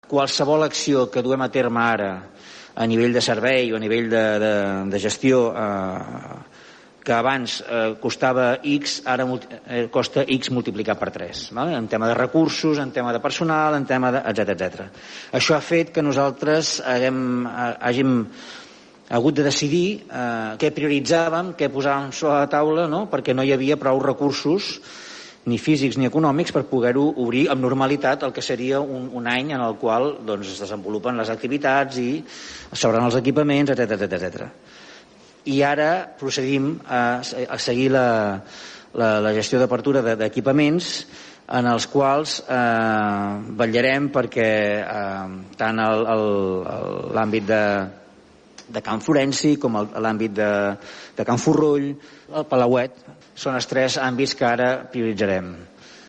En l’últim ple municipal, l’alcalde de Palafolls, Francesc Alemany, explicava que ara es focalitzen els esforços per reobrir el Palauet, Can Florenci i el Forroll.